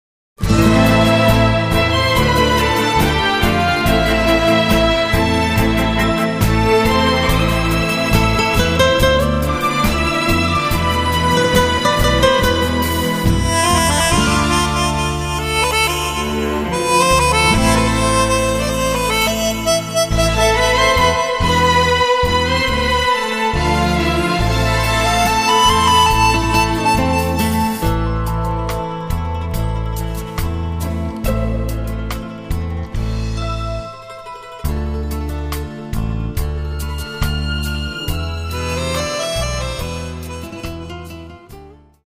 生演奏 音源サンプル(mp3 657kb)